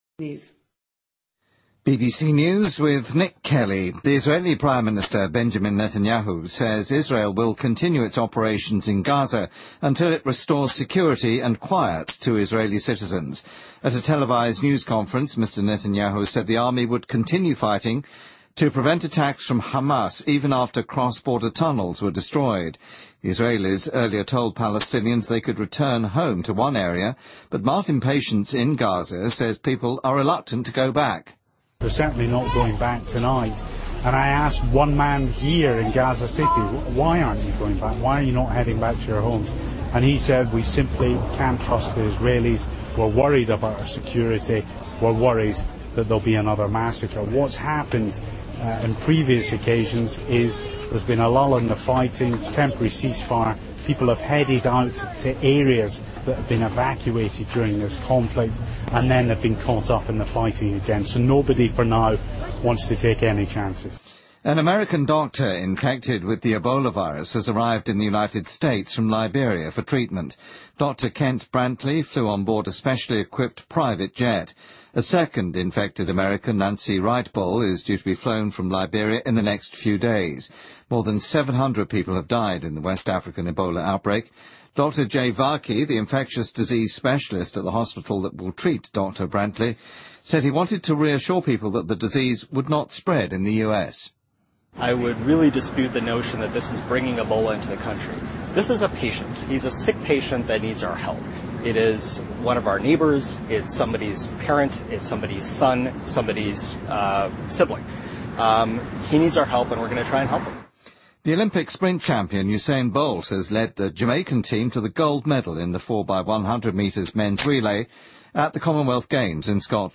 BBC news,法国警方逮捕一名涉嫌从警局总部偷窃50多公斤可卡因的缉毒官员